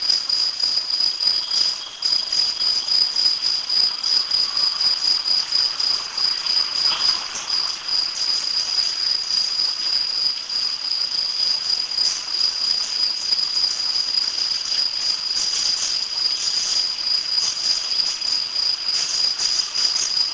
Canto di Modicogryllus (Eumodicogryllus) burdigalensis , Natura Mediterraneo | Forum Naturalistico
Con il mio nuovo giochino ho fatto questa prima registrazione dal balcone di casa e con il microfono direzionale..che ne dite? ches pecie sarà?
Ma credo che il suono risulti anche un pò alterato rispetto all'originale (sicuro che fosse così "fischiettante?"), perchè a questo punto credo che non si possa escludere nemmeno qualche specie di cicala.
è un registratore digitale olympus al quale ho attaccato un giochino di mia figlia, una pistola parabola per sentire suoni
22 circa, io sto al 2 piano e il suono viene dal basso..penso da pini o prato